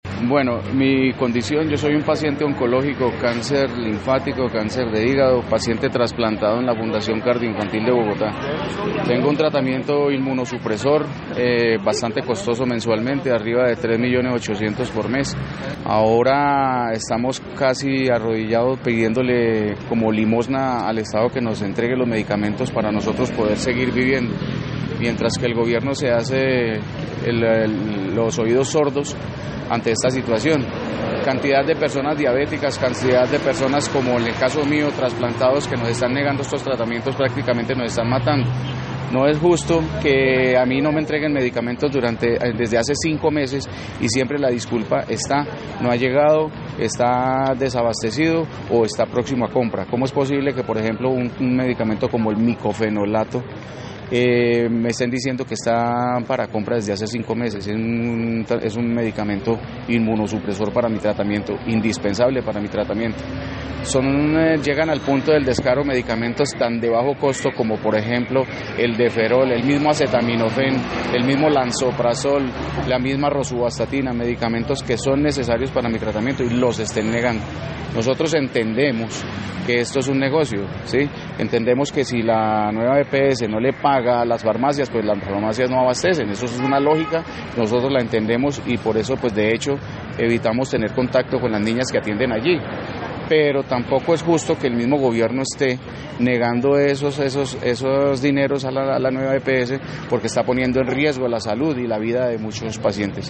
Queja usuario